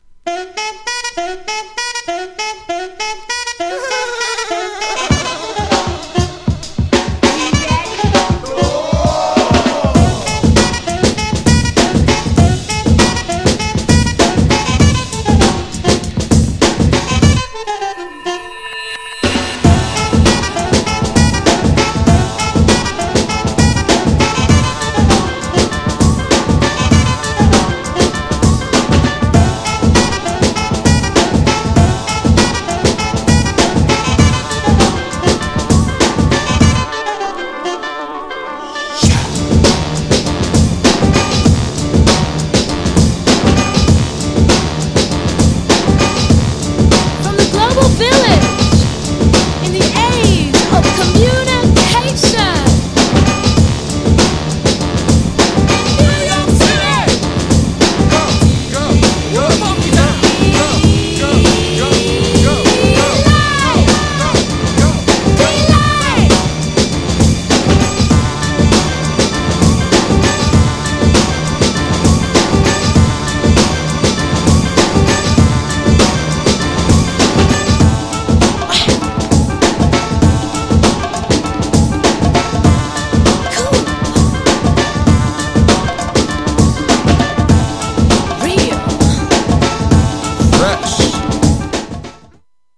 GENRE House
BPM 121〜125BPM